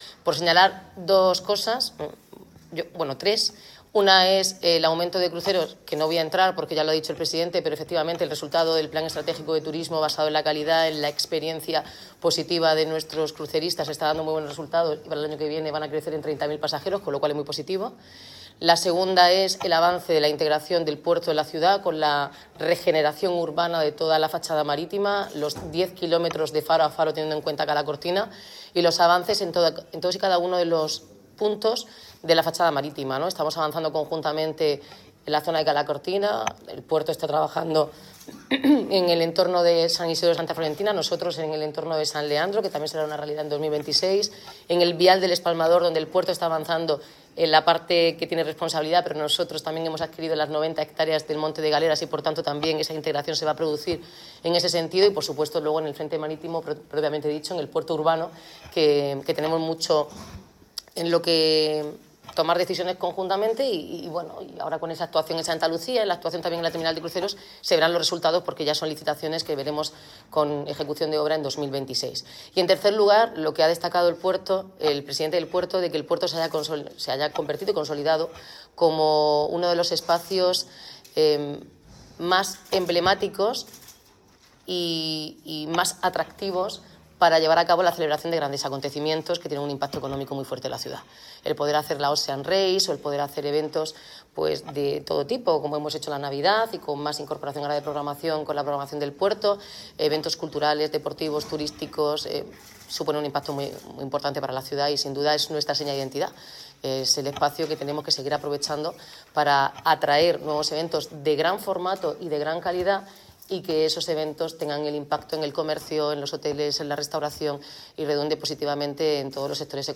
Enlace a Declaraciones de la alcaldesa, Noelia Arroyo, Consejo de Administración del Puerto
Así lo ha expresado la alcaldesa, Noelia Arroyo, este martes 16 de diciembre durante un desayuno con los medios de comunicación convocado por el presidente de la Autoridad Portuaria, Pedro Pablo Hernández, con carácter previo al último Consejo de Administración de la entidad portuaria.